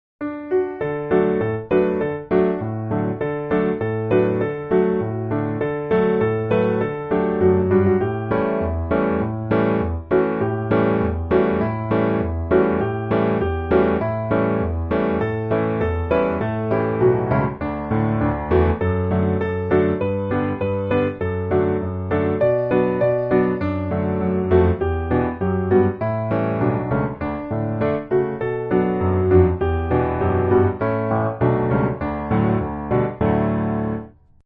D Majeur